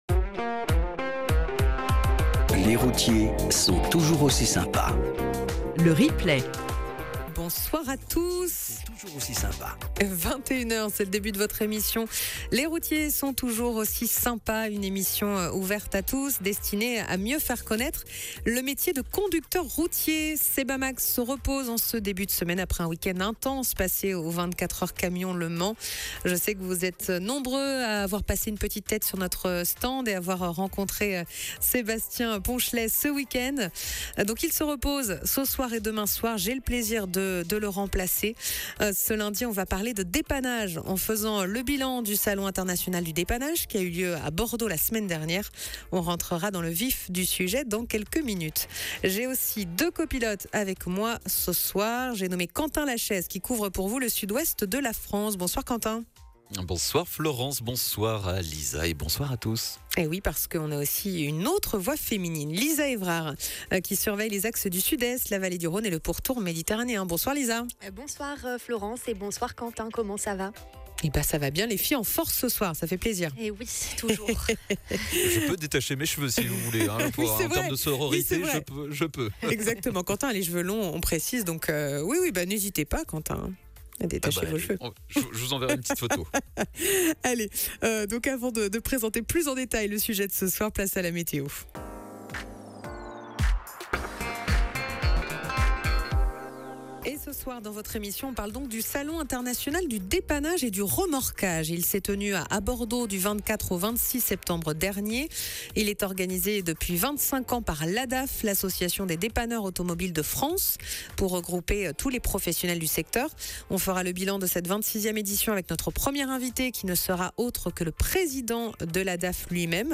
Le salon du dépannage s'est tenu la semaine dernière à Bordeaux, l'occasion de faire toute la lumière sur ce rendez-vous réservé aux professionnels. De nombreux invités pour évoquer le métier de dépanneur aujourd'hui mais aussi demain. Sans oublier vos témoignages.